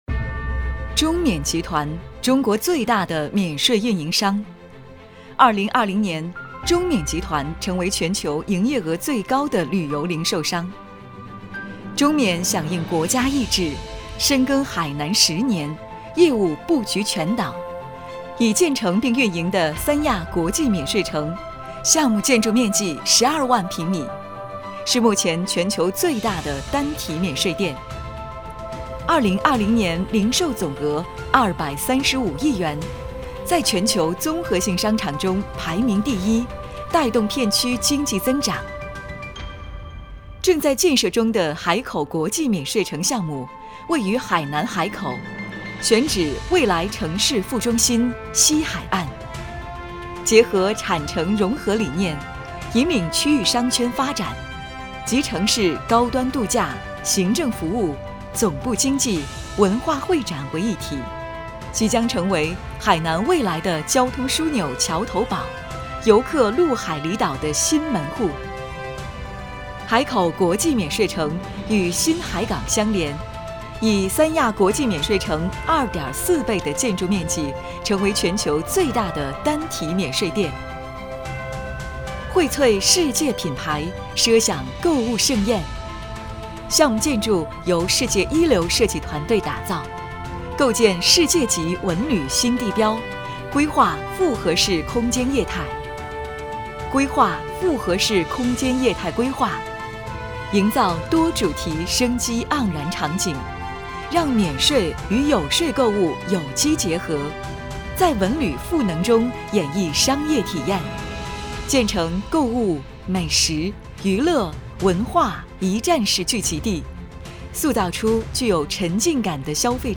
女国190_专题_企业_海口国际免税城_自然.mp3